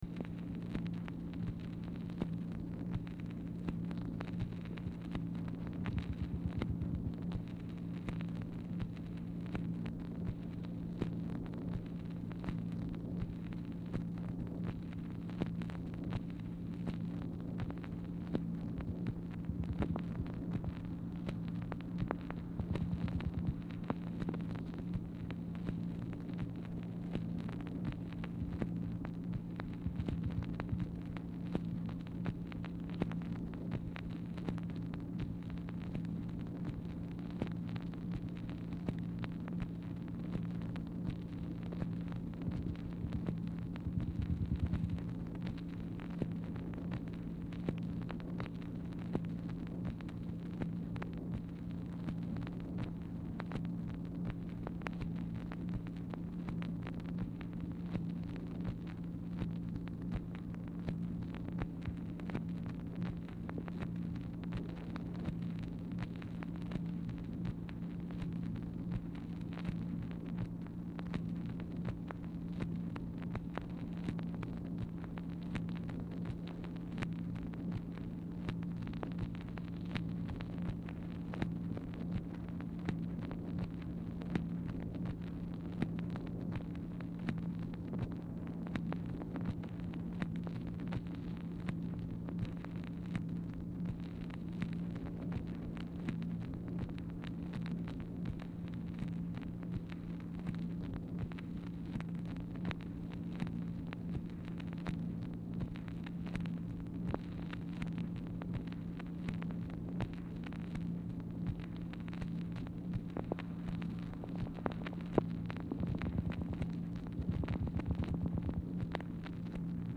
MACHINE NOISE
Oval Office or unknown location
Telephone conversation
Dictation belt